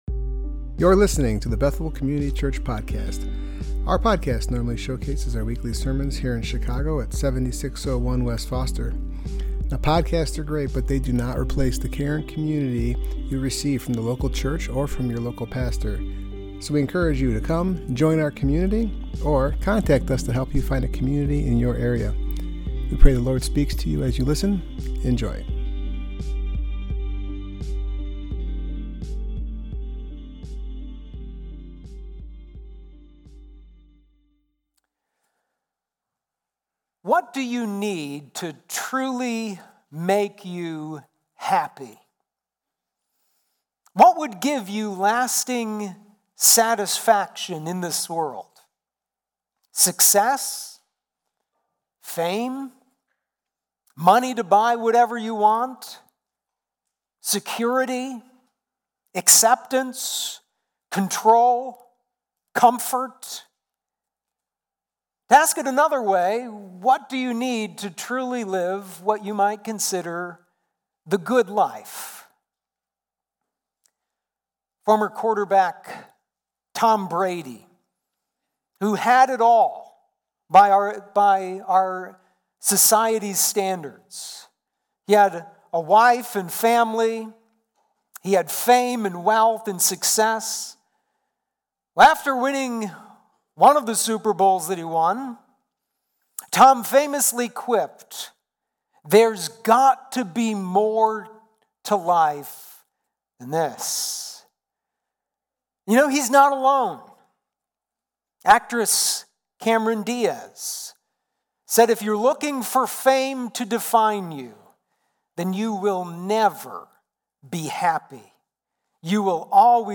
Passage: Ecclesiastes 1:1-11 Service Type: Worship Gathering